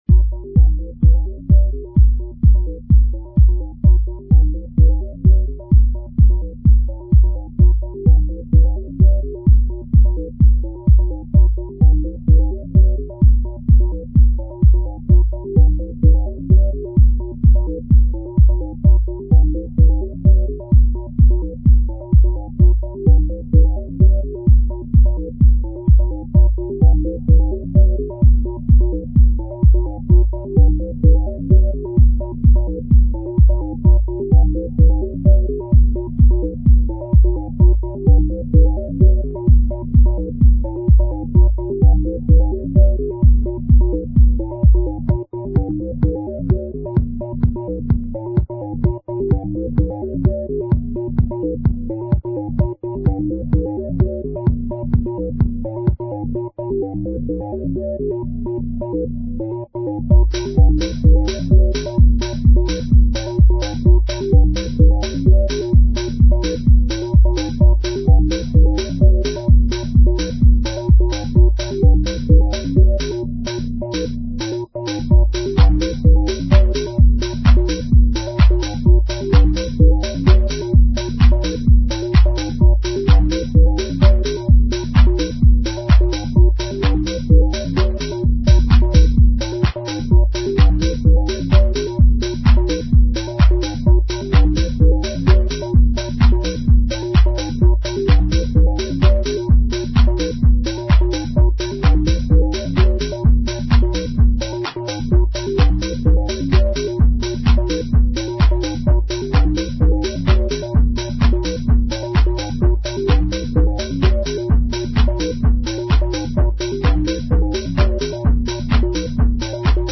Deep synth Techno song, with filtered percussions.
Techno